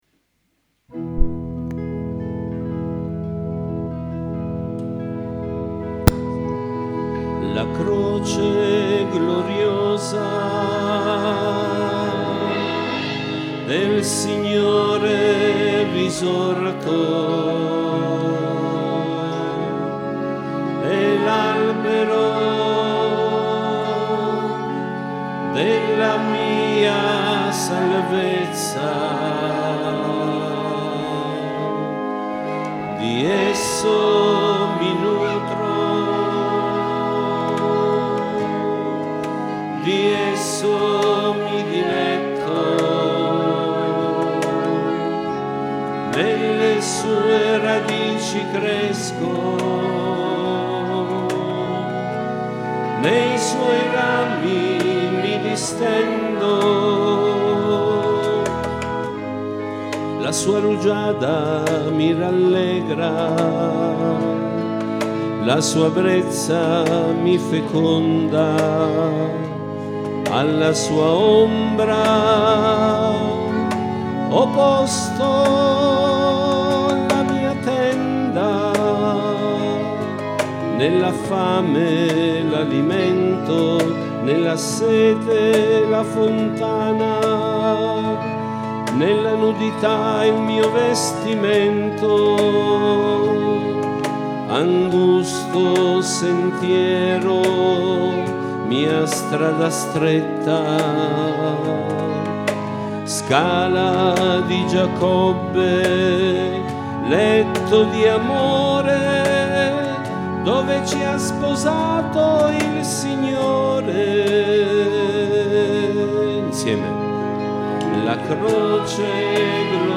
Canti Venerdì Santo 2022